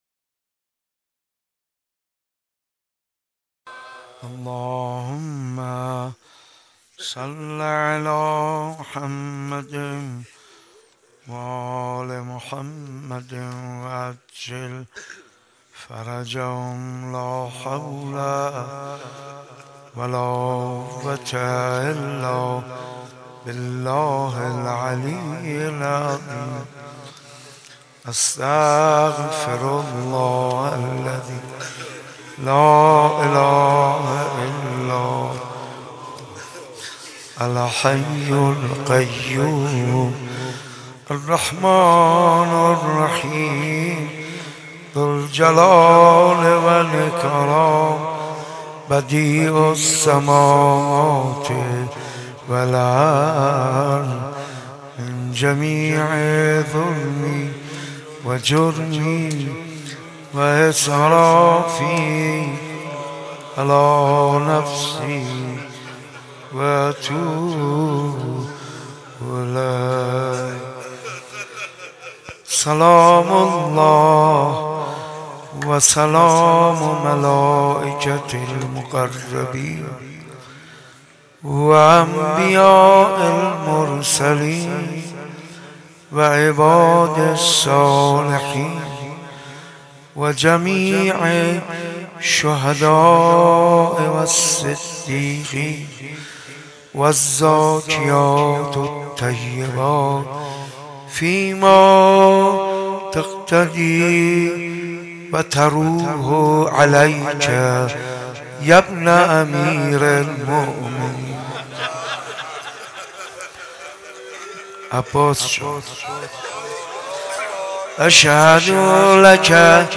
با اینکه پیکر پسرش بوریا شود روضه محمود کریمی